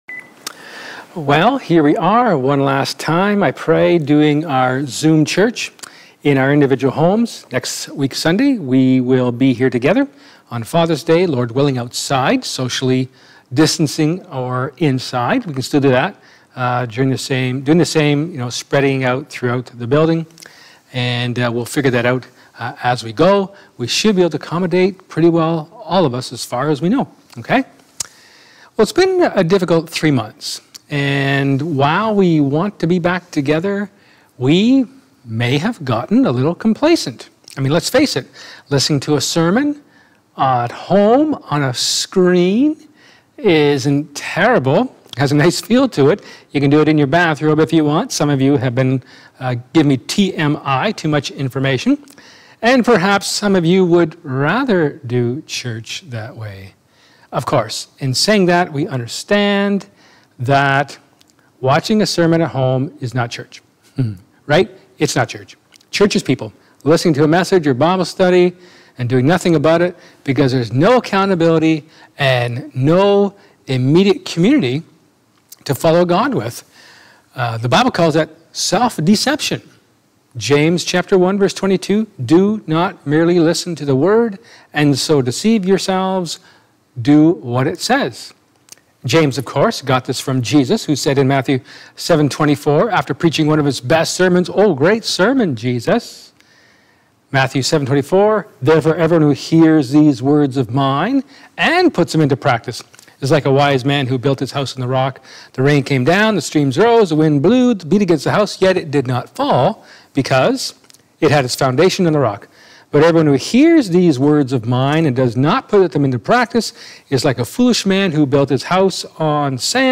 Luke 15:11-32 Service Type: Sermon